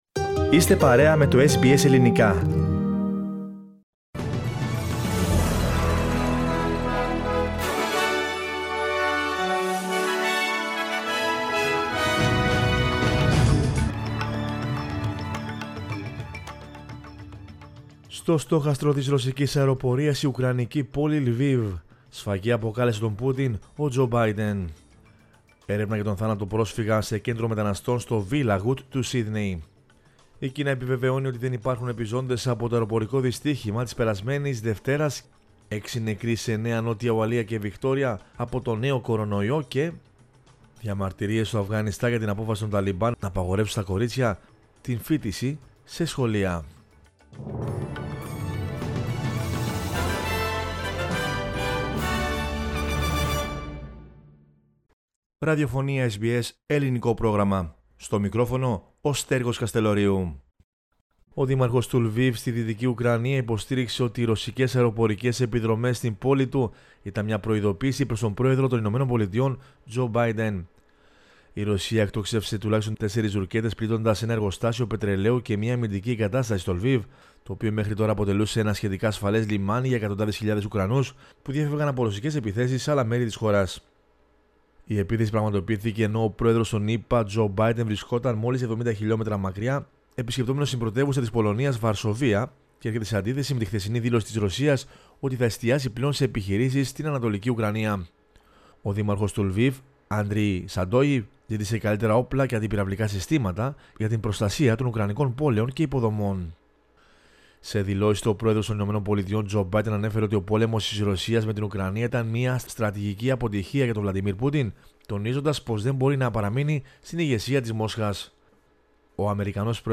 News in Greek from Australia, Greece, Cyprus and the world is the news bulletin of Sunday 27 March 2022.